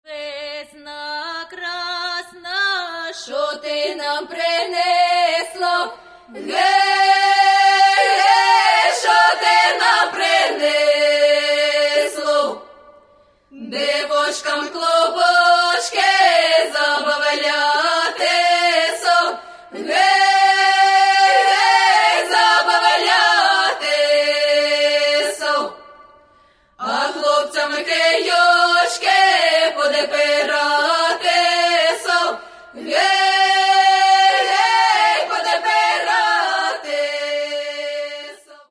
Весна, весняночко. Веснянки та великодні пісні.